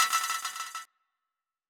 /audio/sounds/Extra Packs/Dubstep Sample Pack/FX/